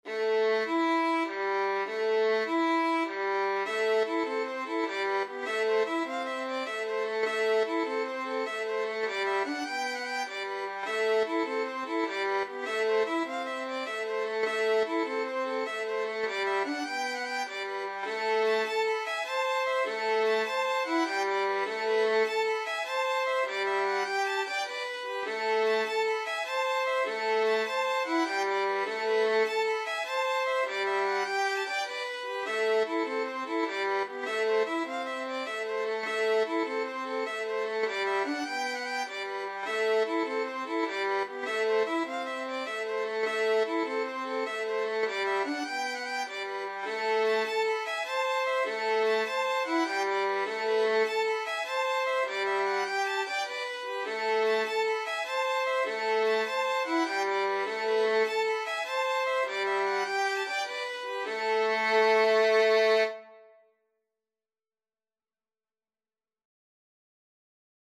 Free Sheet music for Violin Duet
A minor (Sounding Pitch) (View more A minor Music for Violin Duet )
9/8 (View more 9/8 Music)
. = c.100
Traditional (View more Traditional Violin Duet Music)